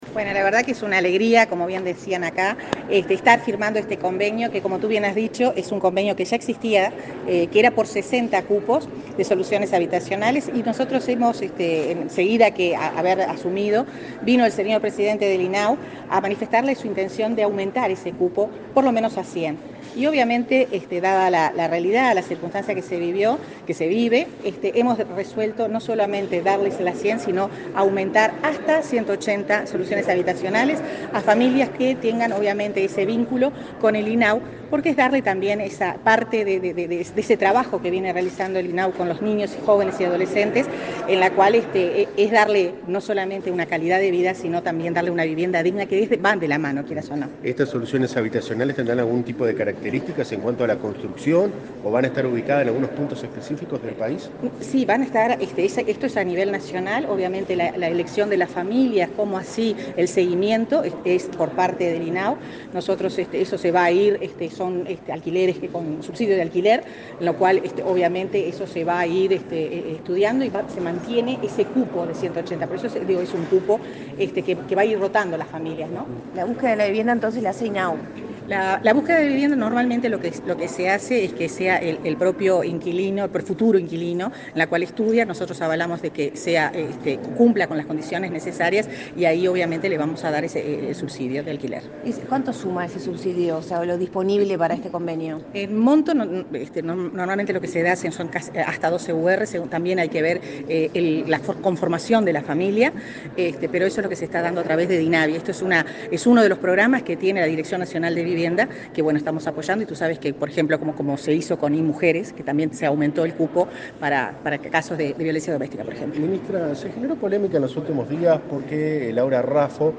Declaraciones de la ministra Irene Moreira
Declaraciones de la ministra Irene Moreira 06/09/2022 Compartir Facebook X Copiar enlace WhatsApp LinkedIn El Ministerio de Vivienda y el Instituto del Niño y Adolescente del Uruguay (INAU) firmaron un convenio para asegurar continuidad al acuerdo que brinda soluciones transitorias de vivienda a familias. Luego, la ministra Irene Moreira dialogó con la prensa.